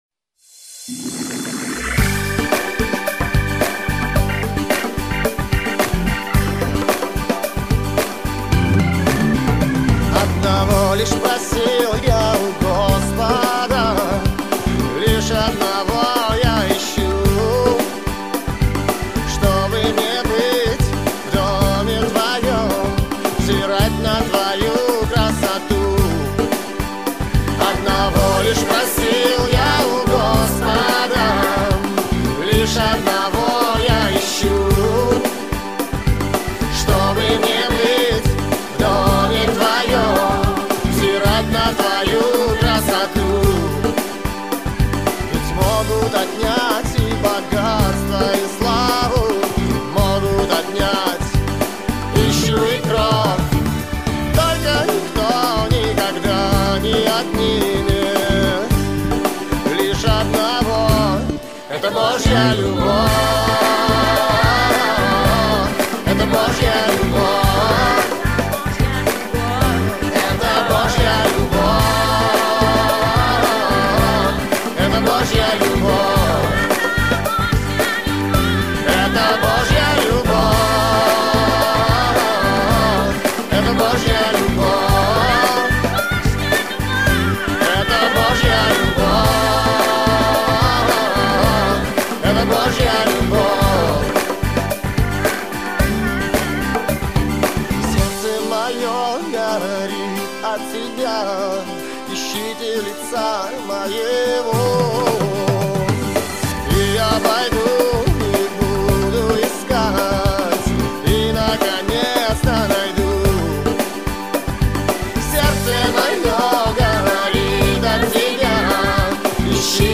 22304 просмотра 8822 прослушивания 1111 скачиваний BPM: 110